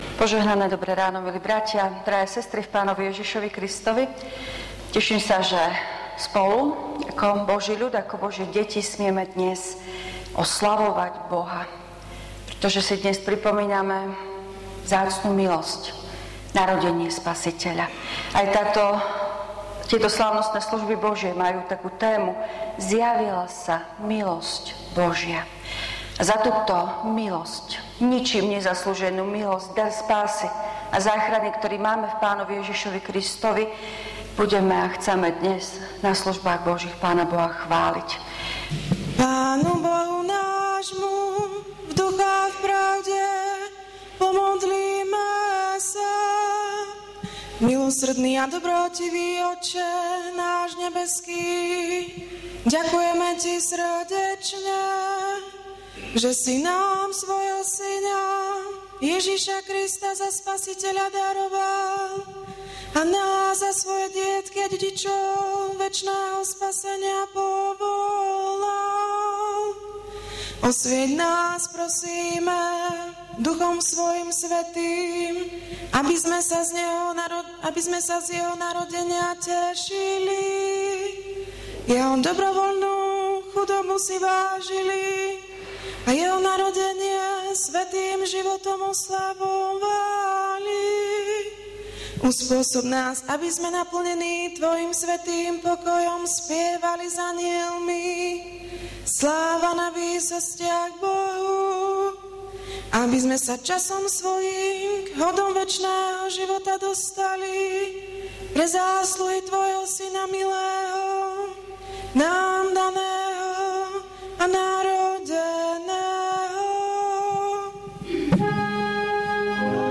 V nasledovnom článku si môžete vypočuť zvukový záznam zo služieb Božích – 1.slávnosť vianočná.